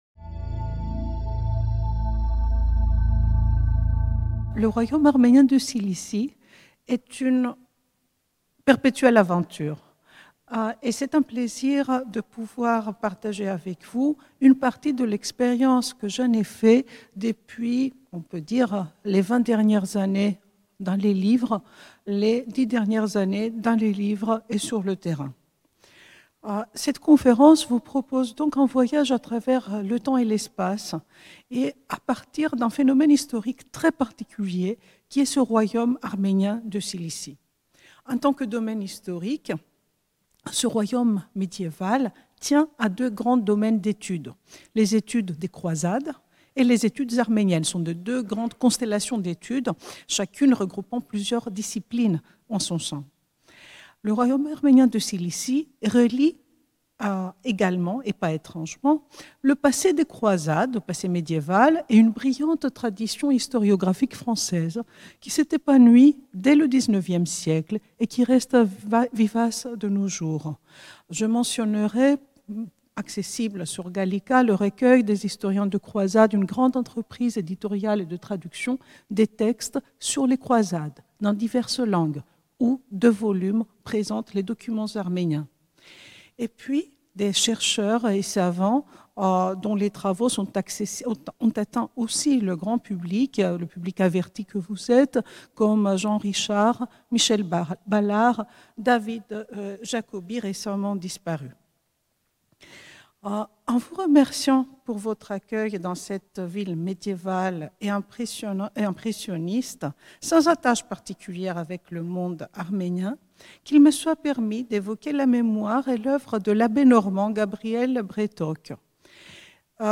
La conférence se propose d’aborder ce phénomène historique à travers sa projection sur le territoire et ses vestiges matéri